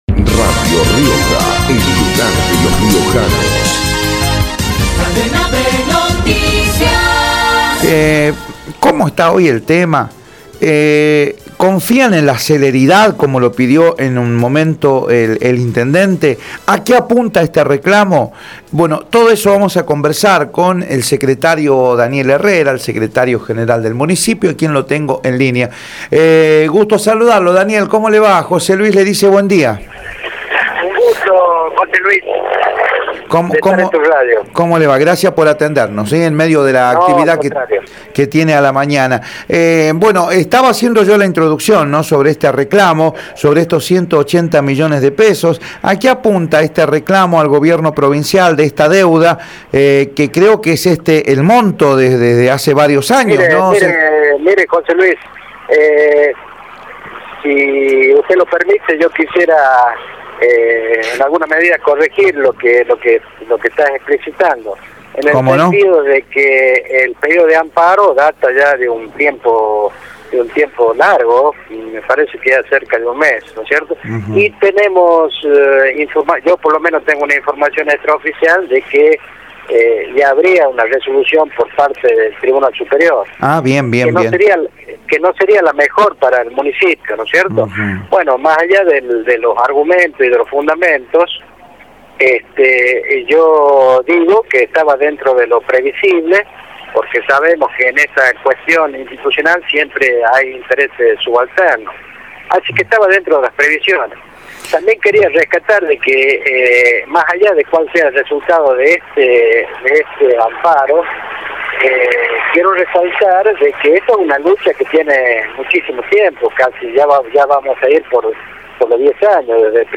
En declaraciones a Radio Rioja, Herrera dijo que como letrado espera que la justicia actúe con madurez e inste a cumplir con las responsabilidades que el gobierno provincial tiene con el municipio.